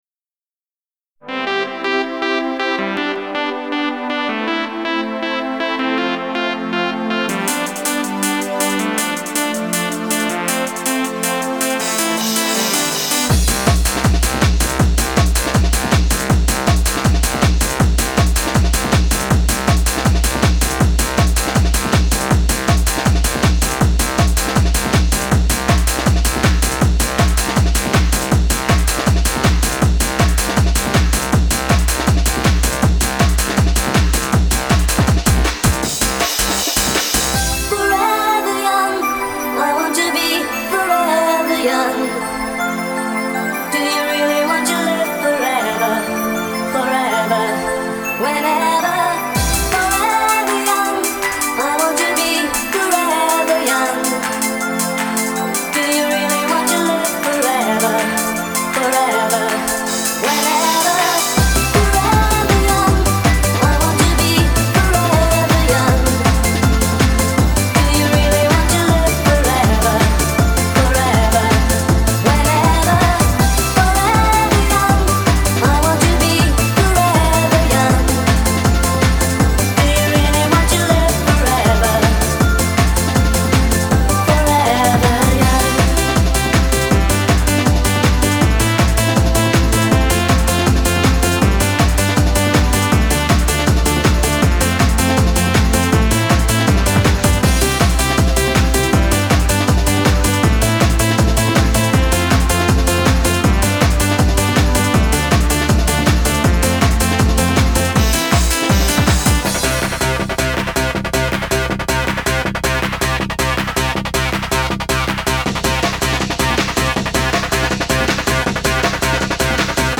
Genre: Hard Trance, Techno, Rave, Hardcore, Dance.